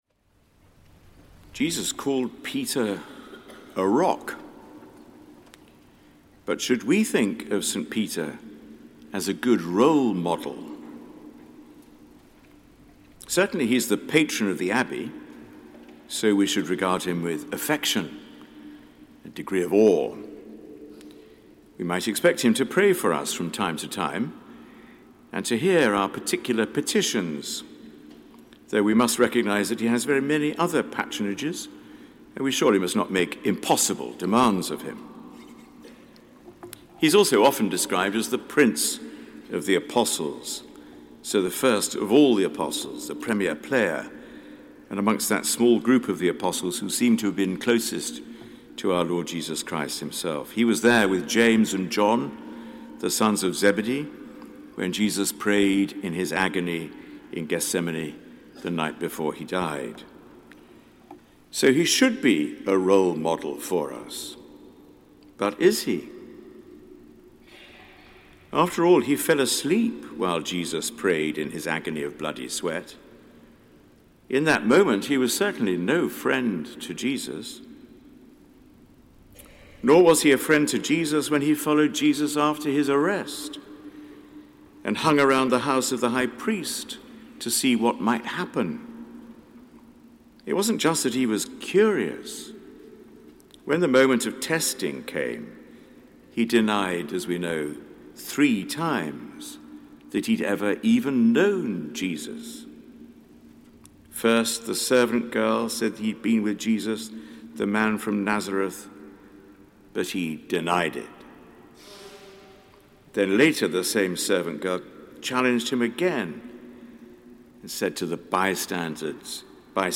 Sermon given at the Sung Eucharist of St Peter
Sermon given at the Sung Eucharist of St Peter, Westminster Abbey, 28th June 2019